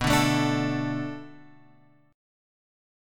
B Minor 7th